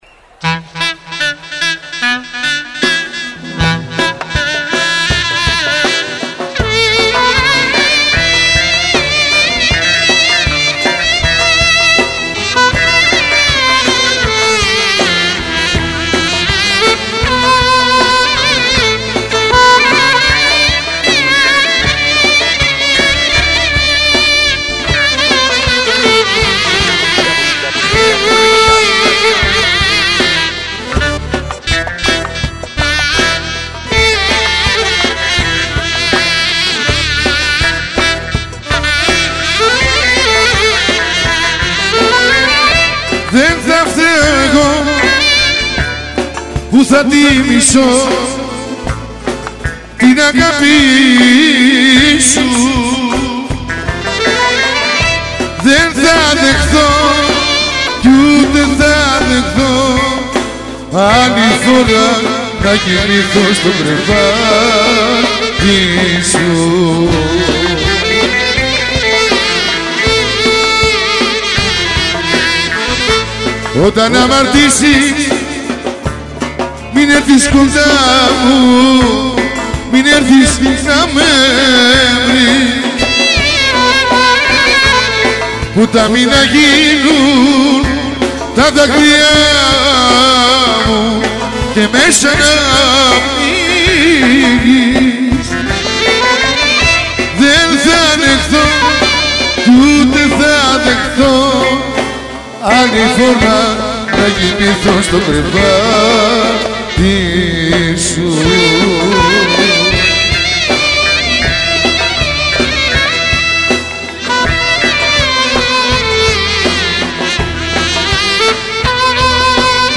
traditional and popular folk songs from all over Greece